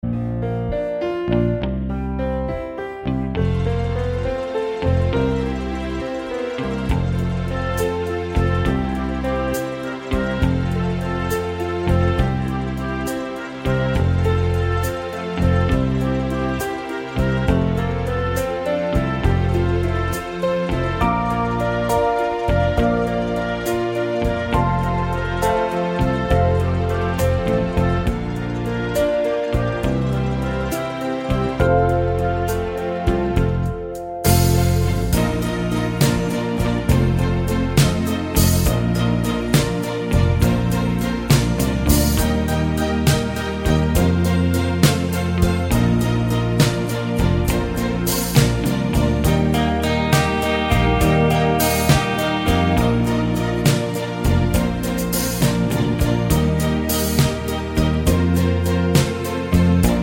no Backing Vocals Soundtracks 3:58 Buy £1.50